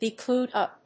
アクセントbe (áll) clúed úp